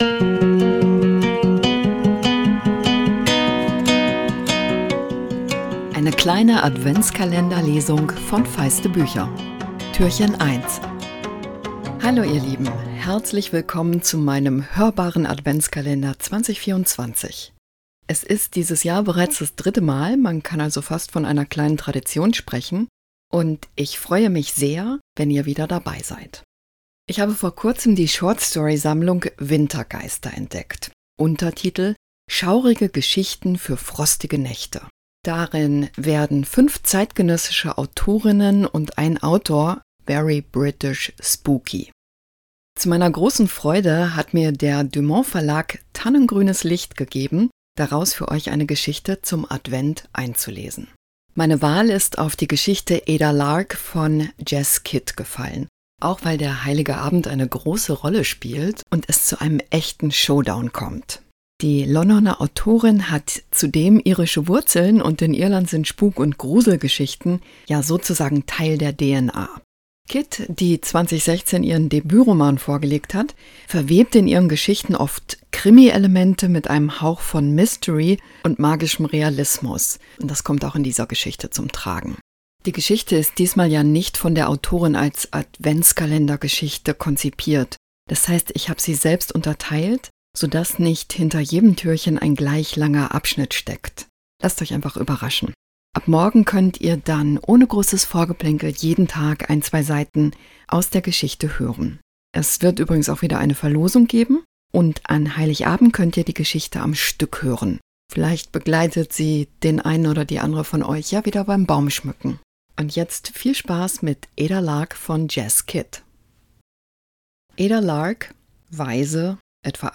Adventskalender-Lesung 2024! Jess Kidd nimmt euch mit ins Leben des Waisenmädchens Ada Lark. Sie lebt bei Madame Bellerose, die einen Plan verfolgt: Sie will mit Adas Hilfe zum berühmtesten Medium Londons werden…